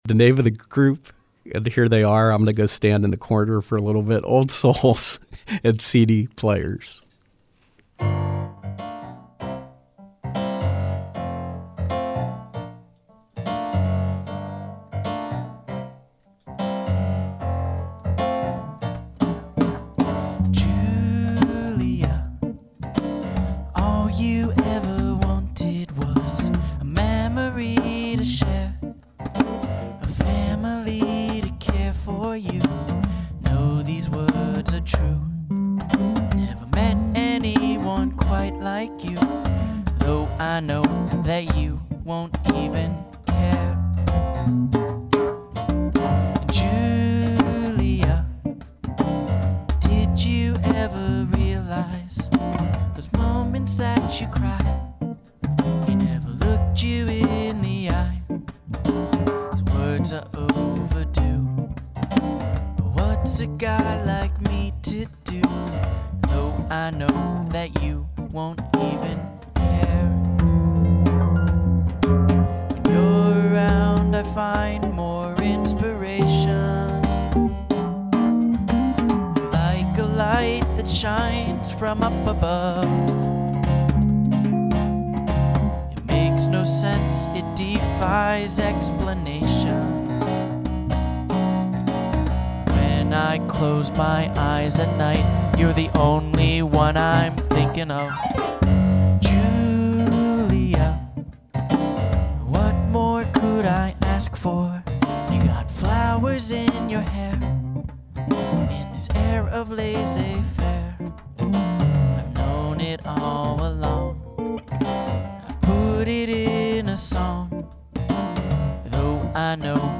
neo-soul psychedelic pop music